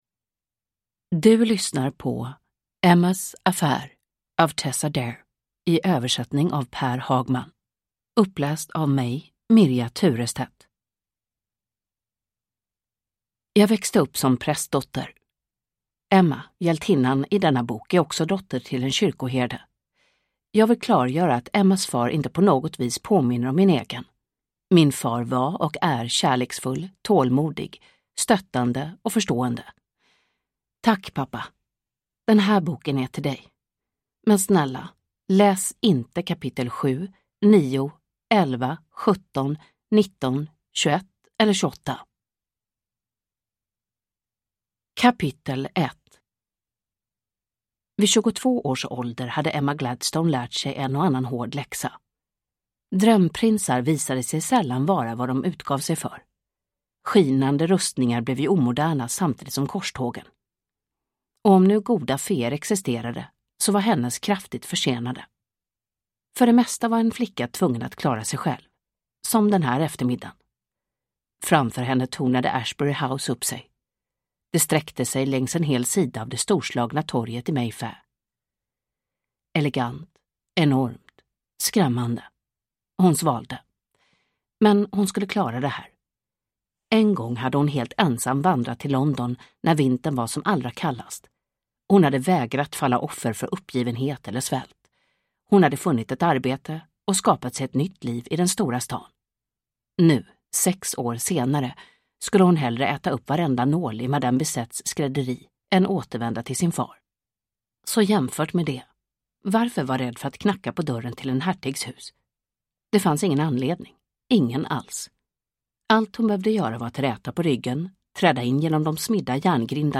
Uppläsare: Mirja Turestedt